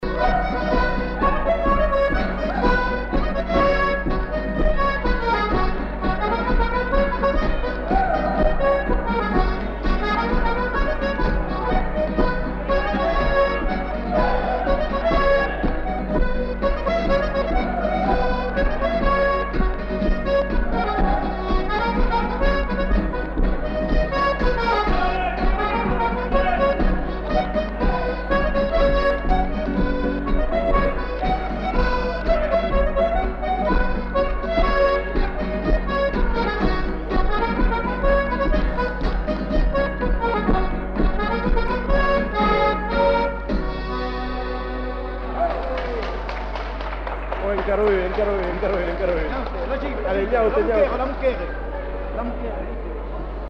Aire culturelle : Savès
Lieu : Samatan
Genre : morceau instrumental
Instrument de musique : accordéon diatonique ; guitare
Danse : rondeau
Notes consultables : Le joueur de guitare n'est pas identifié.